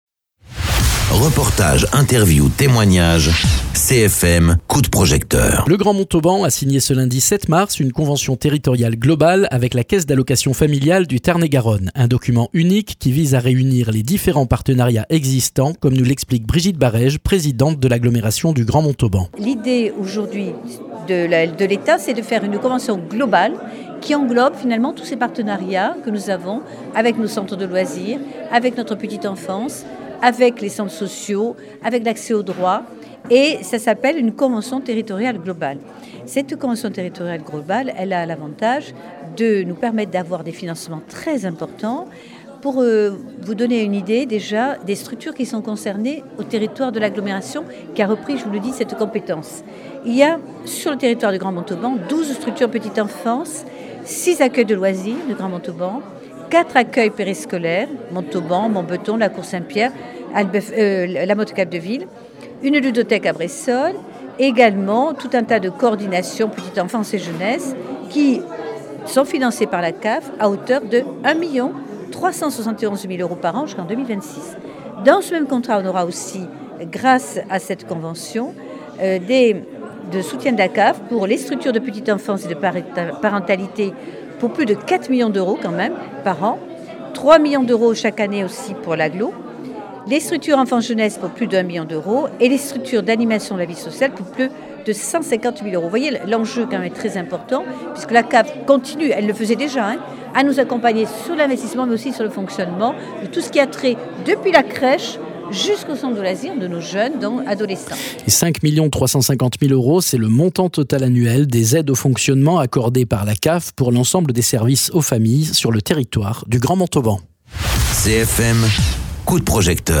Interviews
Invité(s) : Brigitte Barèges Présidente de l’agglomération du Grand Montauban…